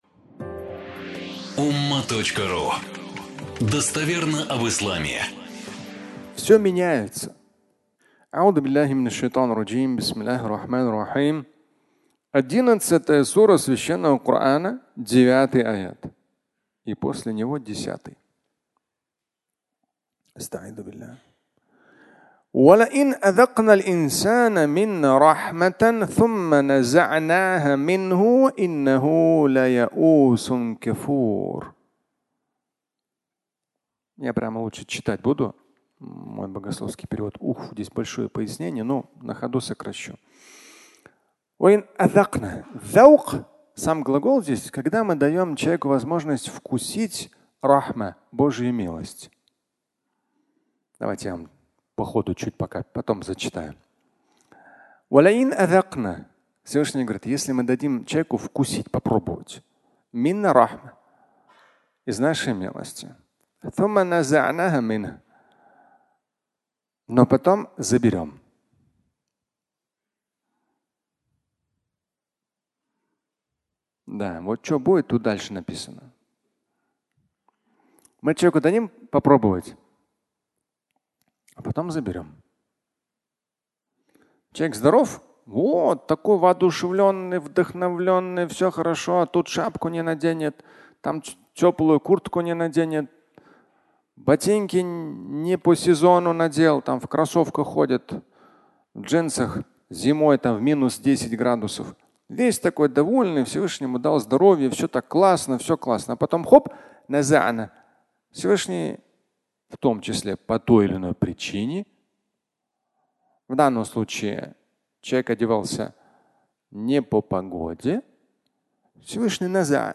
Все меняется (аудиолекция)